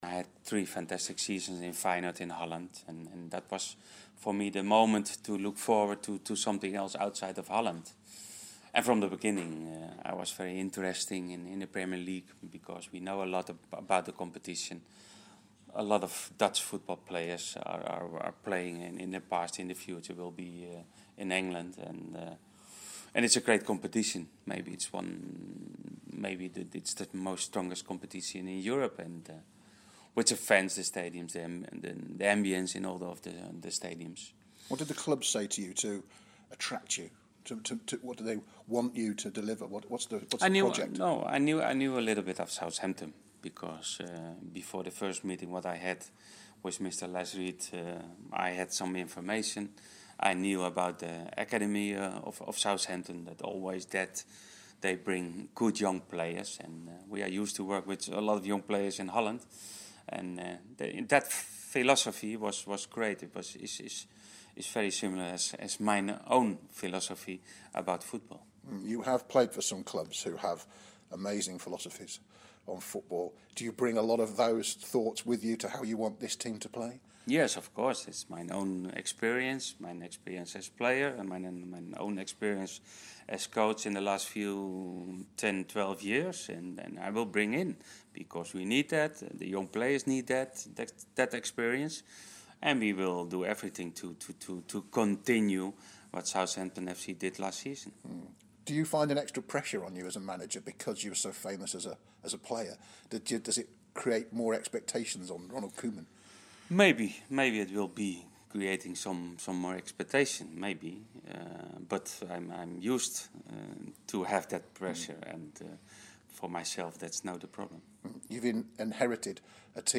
as he talks to the media for first time